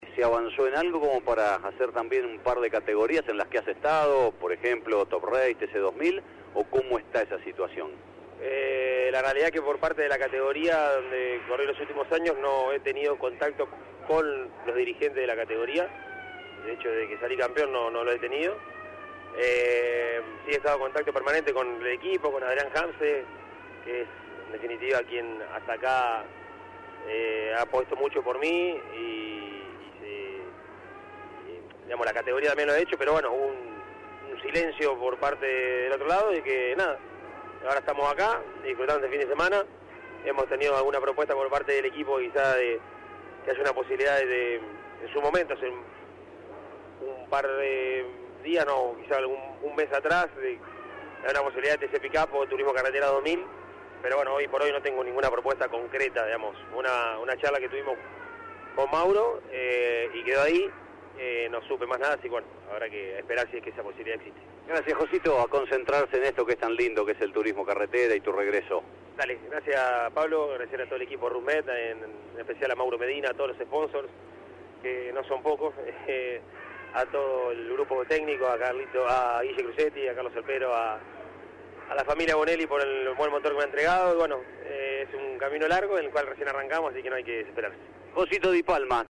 En un alto de sus actividades del fin de semana, Di Palma habló con Campeones, durante la transmisión por AM590 Continental, y se refirió a su continuidad en Turismo Competición 2000 y Top Race V6, categoría esta en la cual se coronó consecutivamente en 2023 y 2024 con el equipo Octanos Competición.